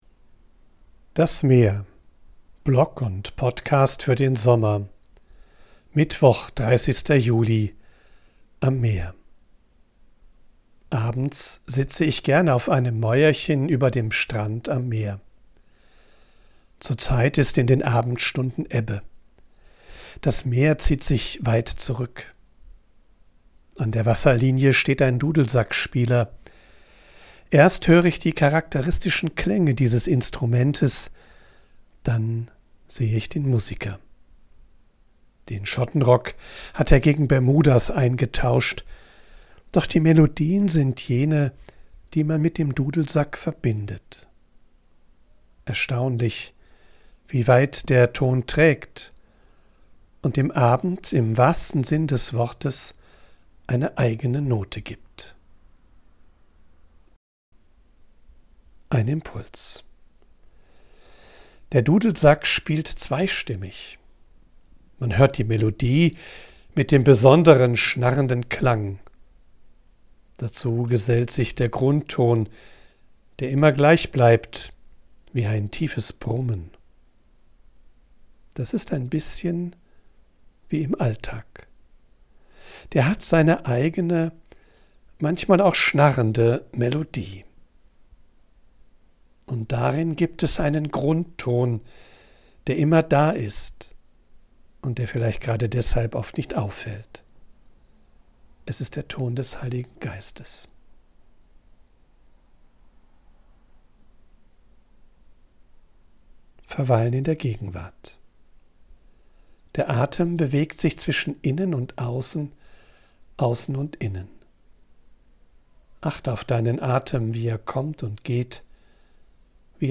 live. Ich bin am Meer und sammle Eindrücke und Ideen. Weil ich
von unterwegs aufnehme, ist die Audioqualität begrenzt. Dafür
mischt sie mitunter eine echte Möwe und Meeresrauschen in die